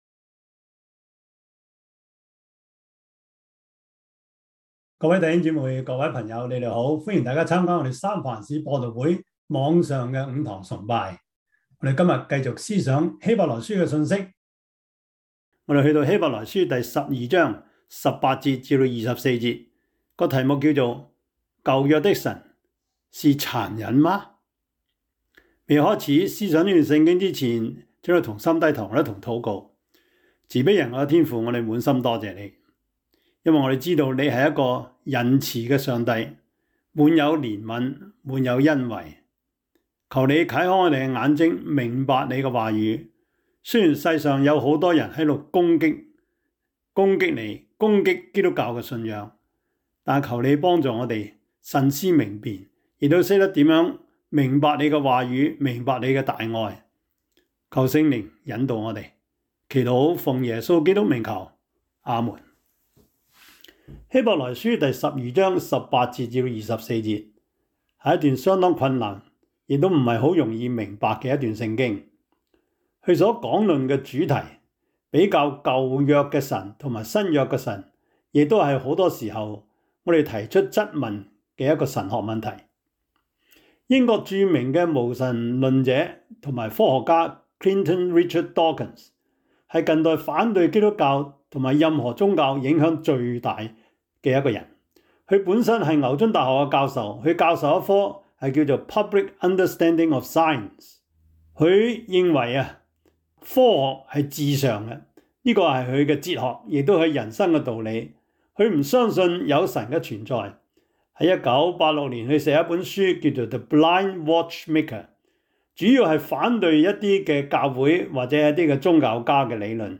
希伯來書12:18-24 Service Type: 主日崇拜 希 伯 來 書 12:18-24 Chinese Union Version